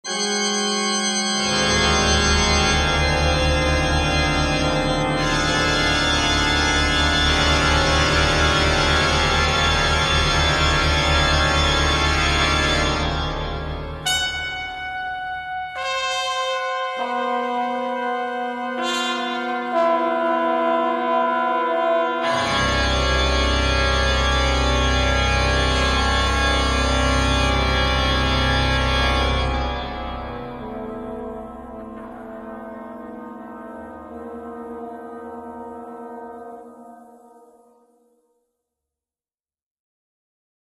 Australian, Organ